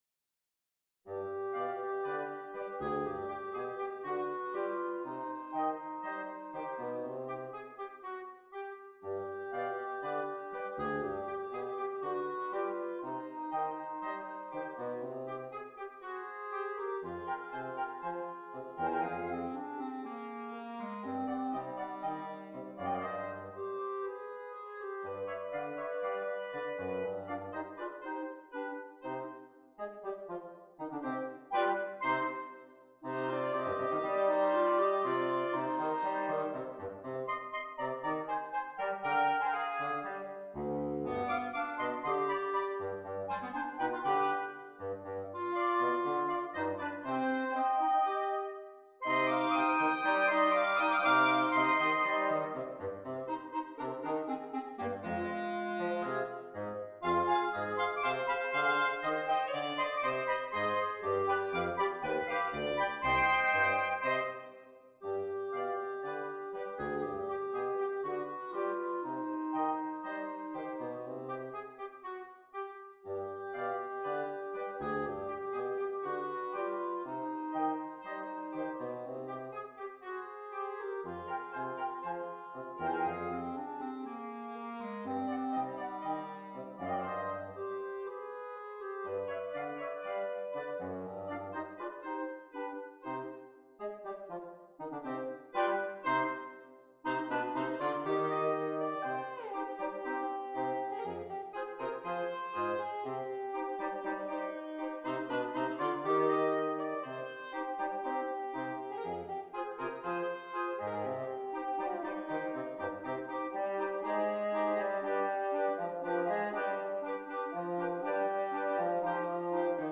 Interchangeable Woodwind Ensemble
PART 1 - Flute, Oboe, Clarinet
PART 3 - Clarinet, Alto Saxophone
PART 4- Clarinet, Alto Saxophone, Tenor Saxophone, F Horn
PART 5- Bass Clarinet, Bassoon, Baritone Saxophone